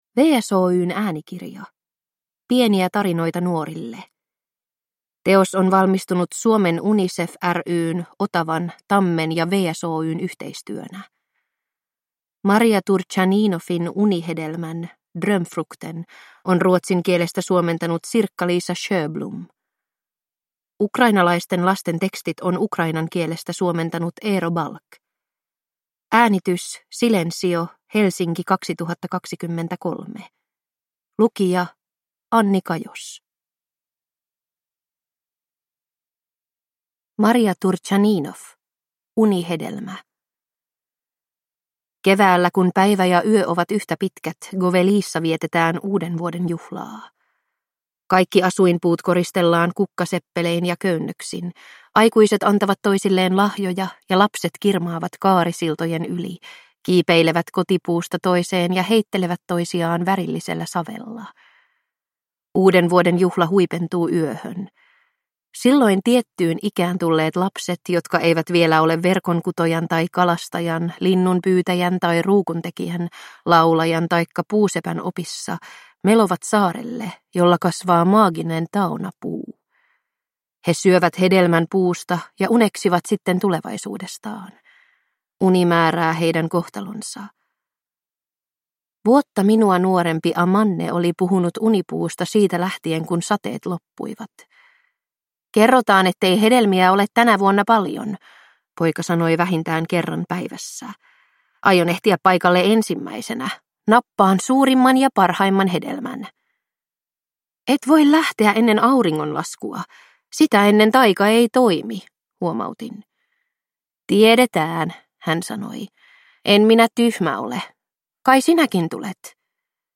Pieniä tarinoita nuorille – Ljudbok – Laddas ner